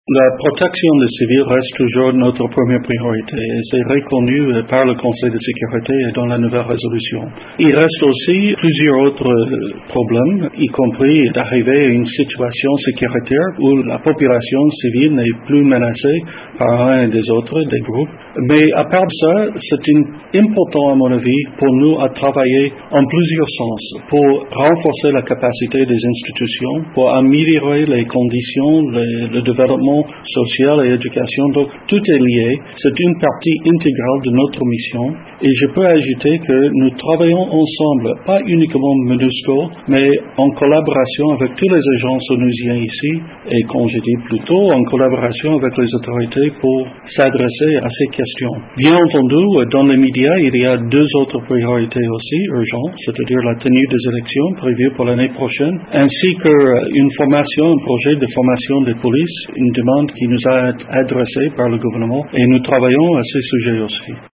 «La protection des civils,  le renforcement des capacités des institutions et le soutien à la RDC dans l’organisation de prochaines élections sont parmi les objectifs auxquels la Monusco va s’atteler », a déclaré le Représentant spécial du secrétaire général des Nations unies en RDC dans une interview accordée à Radio Okapi au moment où il prend ses nouvelles fonctions à la tête de la Mission de l’ONU pour la stabilisation de la RDC.